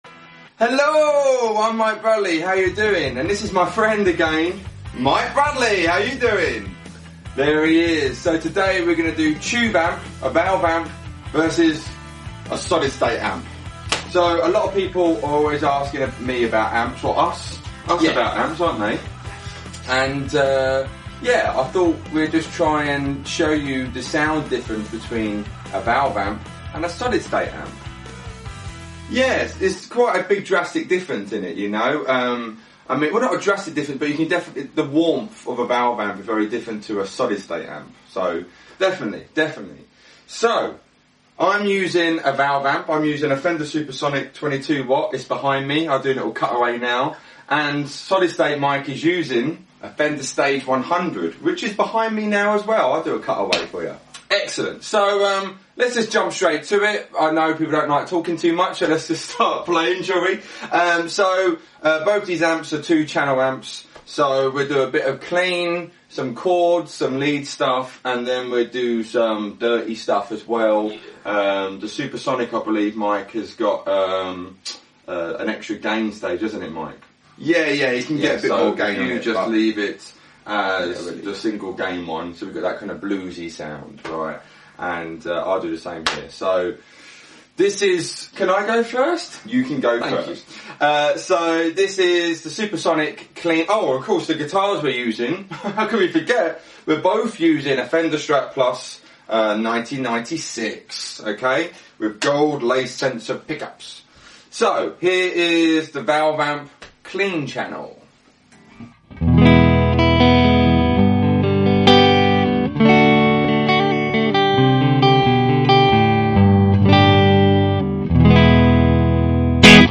NPap Tests: Analog vs Digital blind test